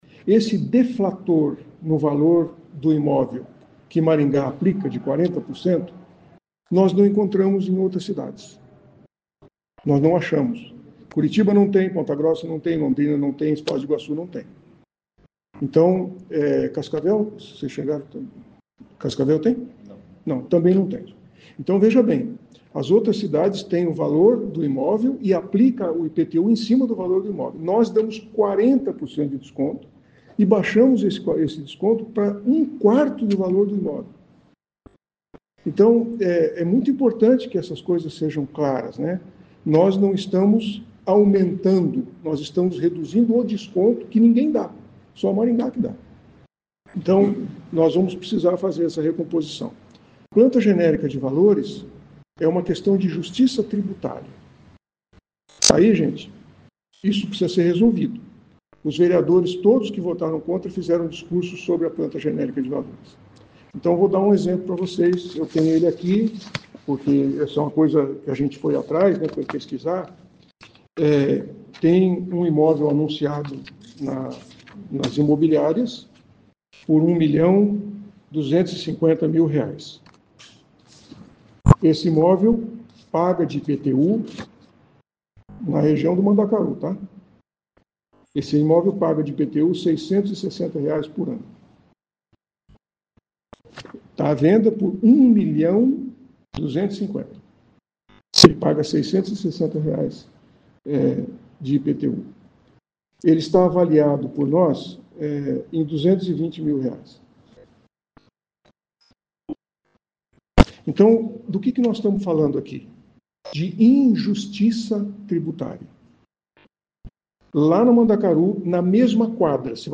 Prefeito Sílvio Barros reúne a imprensa para tirar dúvidas sobre IPTU